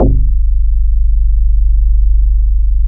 WOOD BASS 2.wav